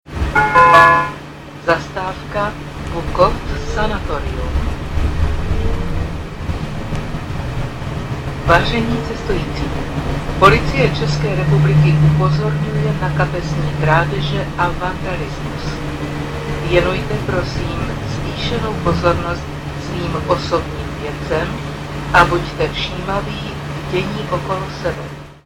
Trolejbusy totiž disponují přehlášenými zastávkami a dalšími zvukovými sekvencemi.
- Ukázku hlášení o krádežích si